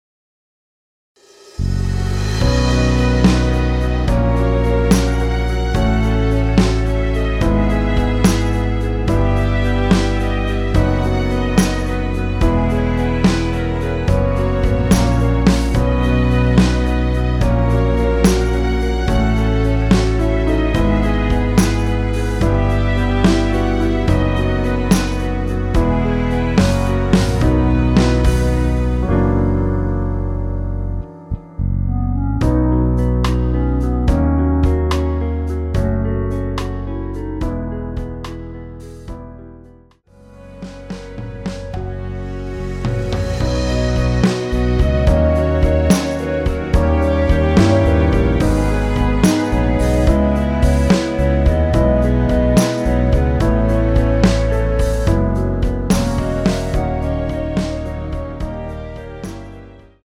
원키에서(-1)내린 멜로디 포함된 MR입니다.(미리듣기 확인)
◈ 곡명 옆 (-1)은 반음 내림, (+1)은 반음 올림 입니다.
앞부분30초, 뒷부분30초씩 편집해서 올려 드리고 있습니다.